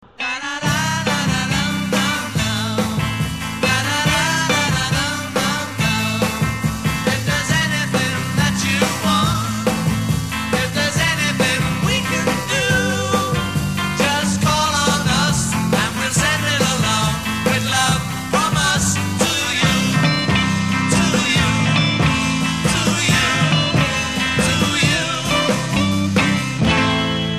BBC Paris Theatre, London
vocals and harmonica
vocals and bass
guitar
drums
Intro 0:00 4 vocal doubling guitar
A Verse 0: 7 doubling and harmony a